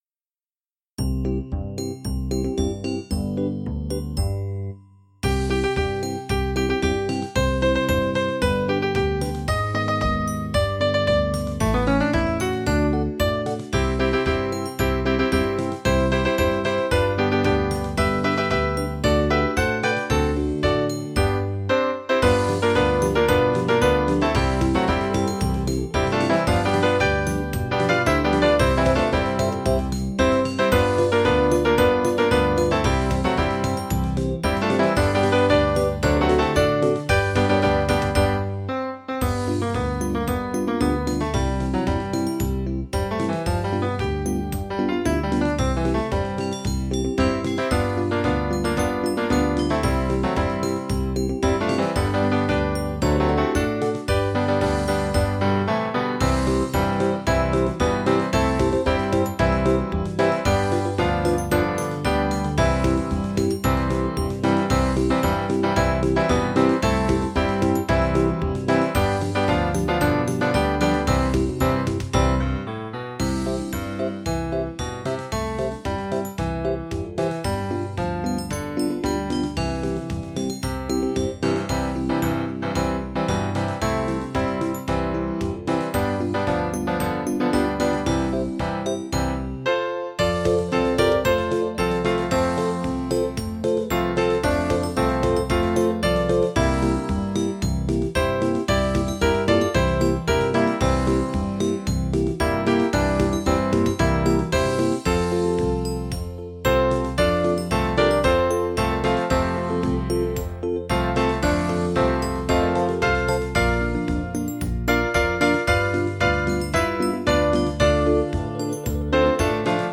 PLAY (mid)
8- beat intro.